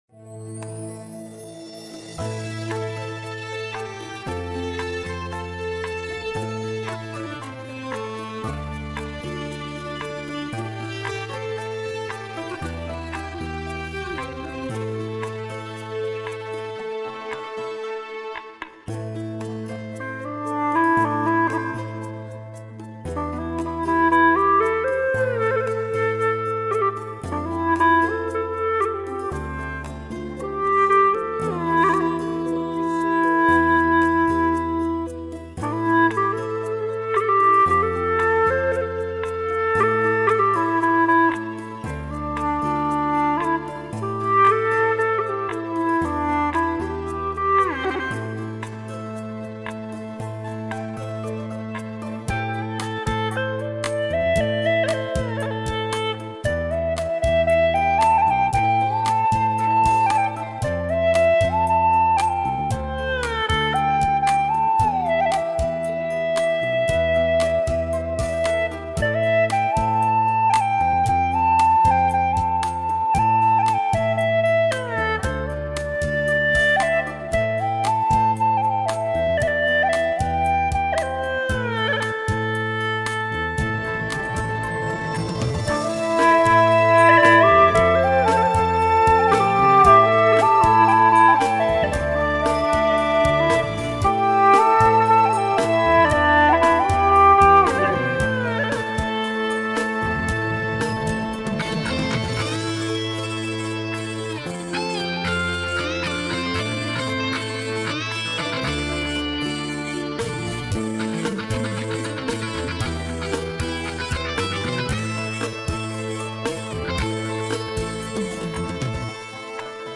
调式 : C 曲类 : 流行